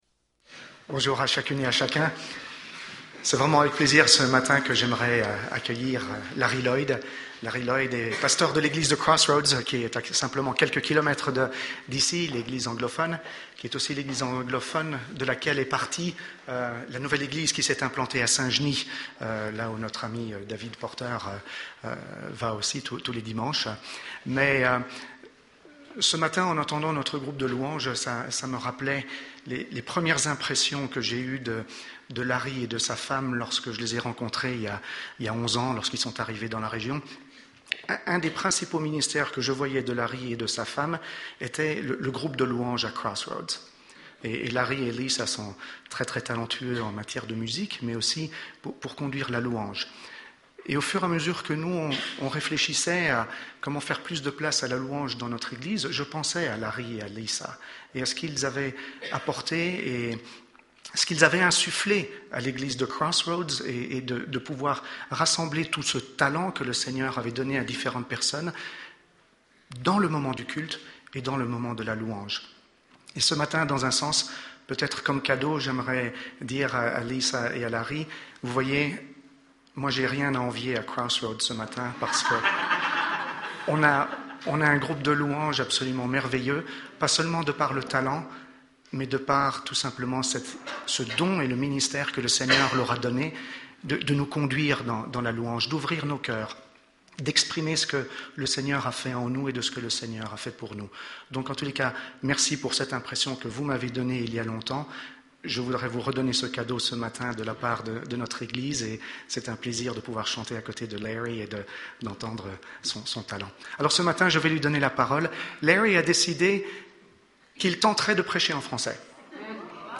Culte du 28 avril